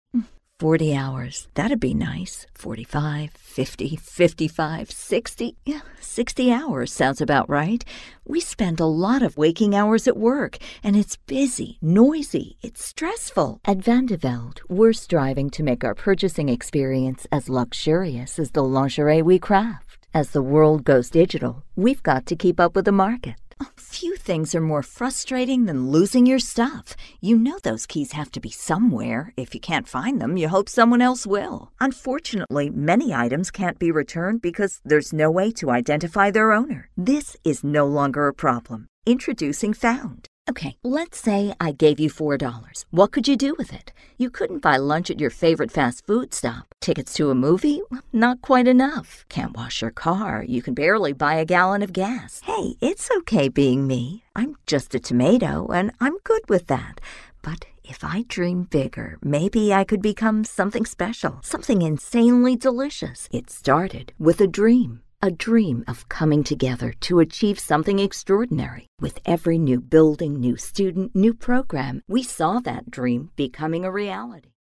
Female
American English (Native) , Canadian English (Native) , British English
Assured, Authoritative, Bright, Character, Confident, Corporate, Deep, Engaging, Friendly, Gravitas, Natural, Posh, Reassuring, Smooth, Soft, Wacky, Warm, Witty, Versatile
Commercial.mp3
Microphone: Sennheiser 416
Audio equipment: Whisperroom Booth, Avid MBox preamp